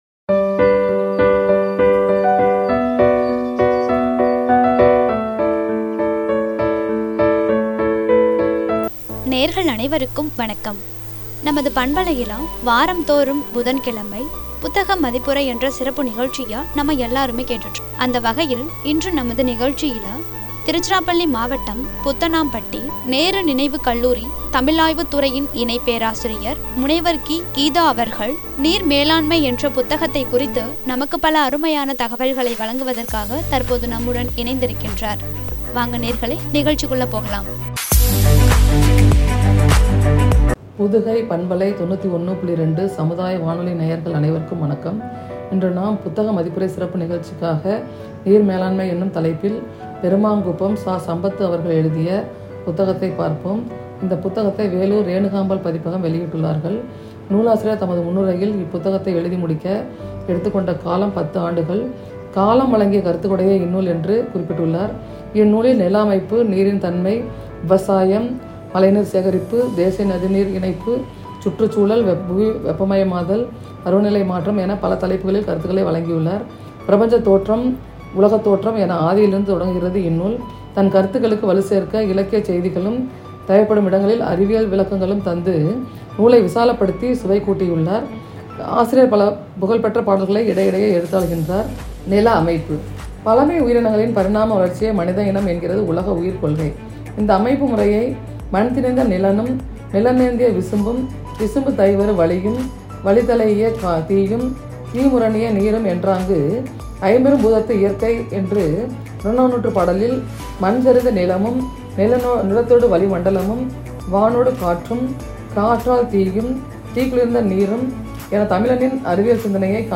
“நீர் மேலாண்மை” (புத்தக மதிப்புரை பகுதி 95) என்ற தலைப்பில் வழங்கிய உரை.